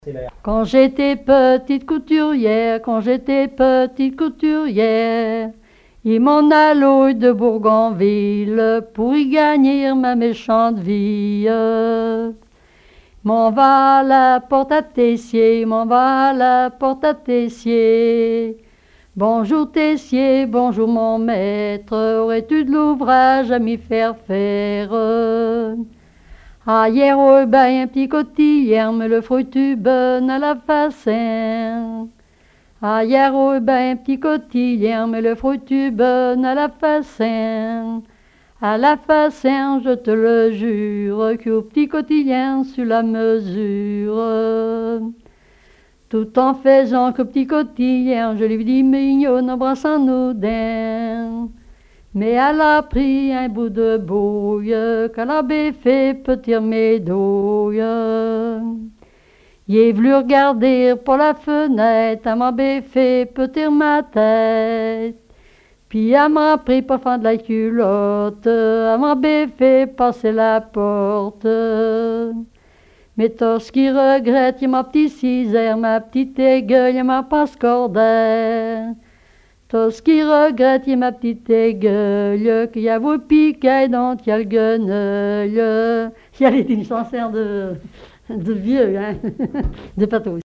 danse : ronde à la mode de l'Epine
collecte en Vendée
Témoignages et chansons traditionnelles
Pièce musicale inédite